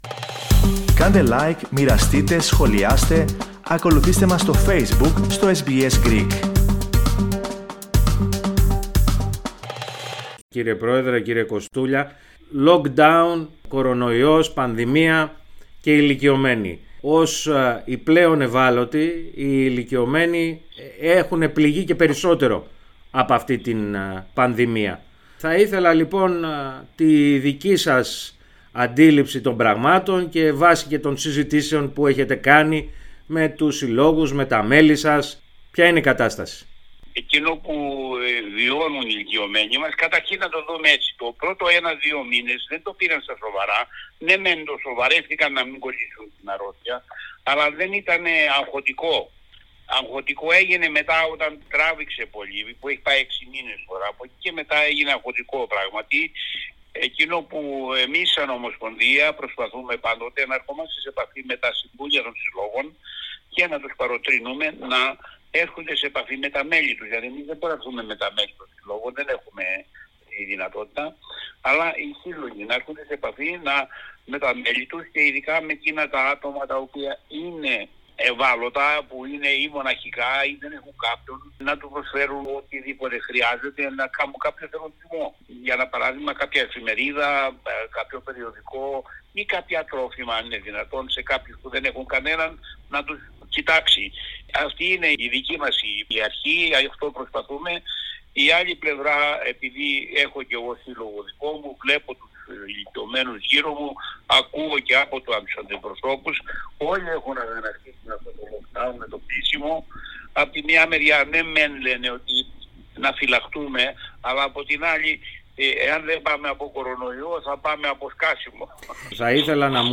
μίλησε σχετικά στο Ελληνικό Πρόγραμμα της ραδιοφωνίας SBS.